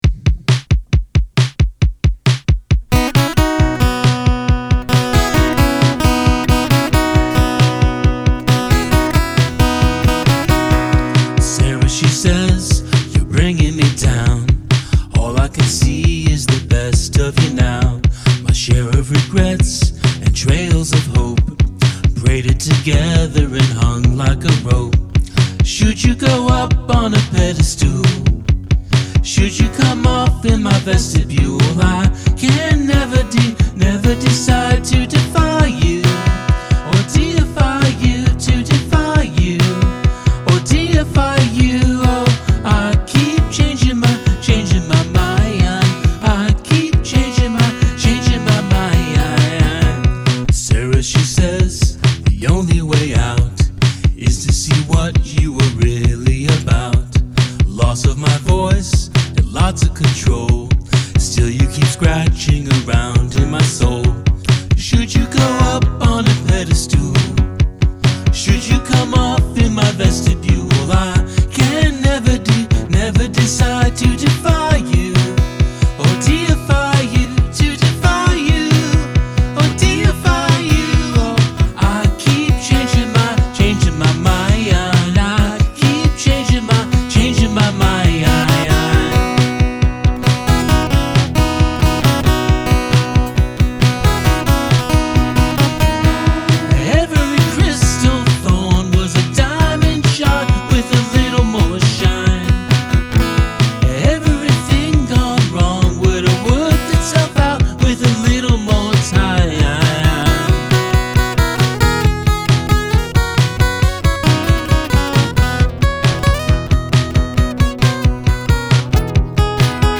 Use a palindromic musical phrase of at least 6 notes total (melody must be a palindrome but rhythm can change)